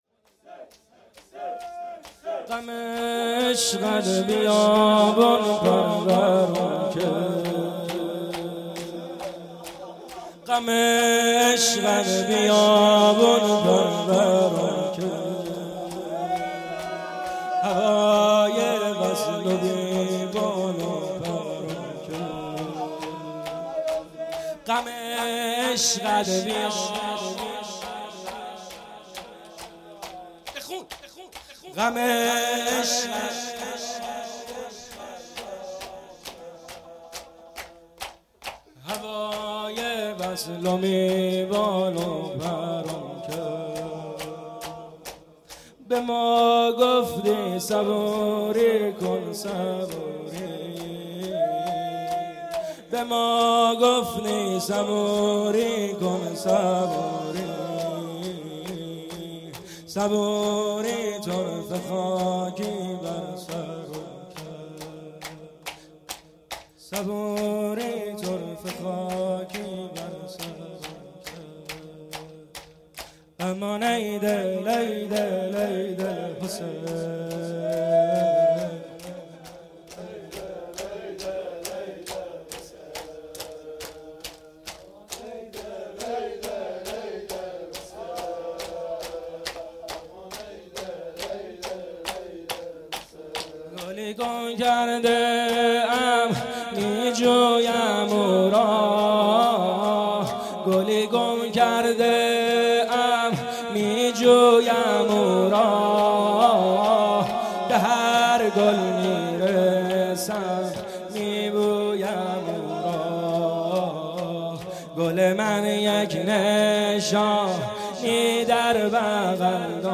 شور پایانی- غم عشقت بیابون پرورم کرد